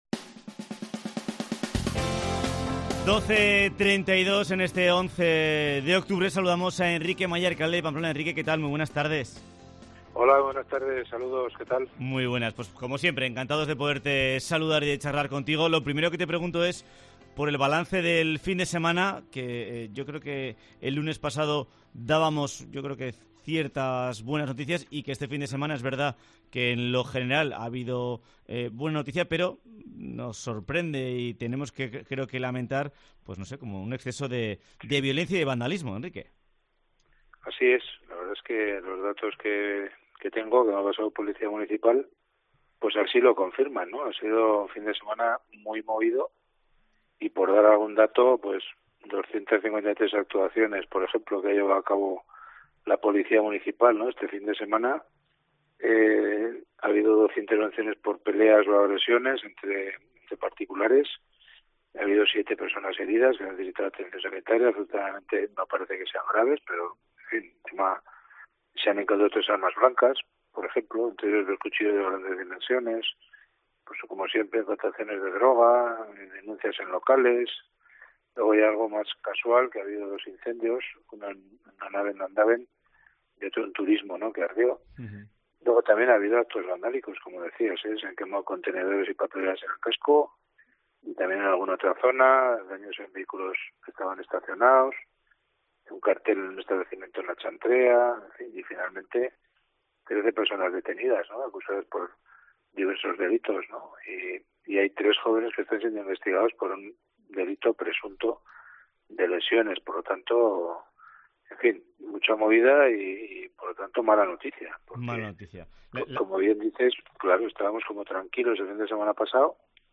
Entrevista con Enrique Maya, alcalde Pamplona